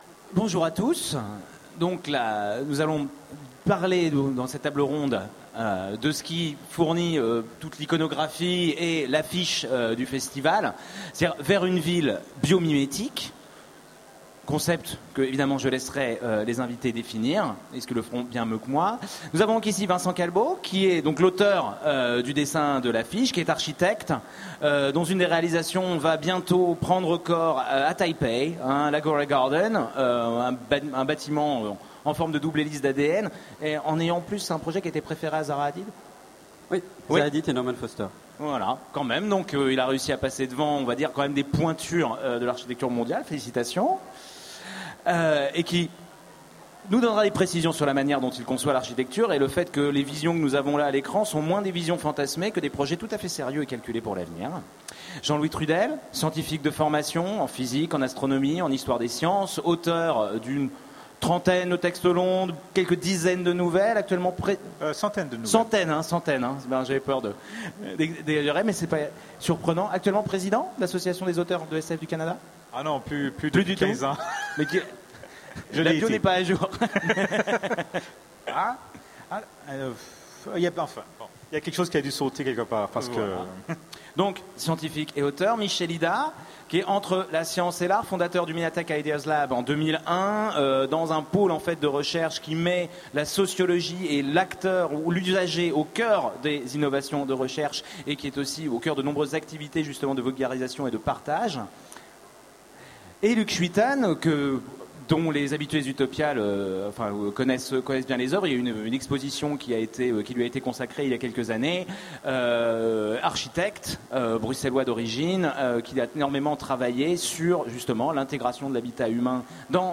Utopiales 13 : Conférence Vers une ville biomimétique